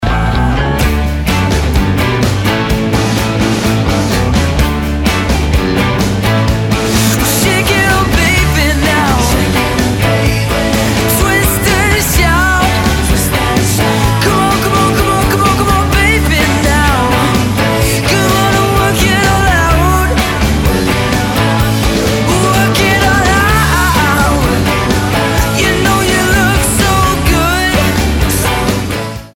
в исполнении молодого бойз-бенда!